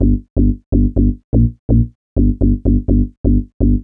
cch_bass_loop_detroit_125_Bb.wav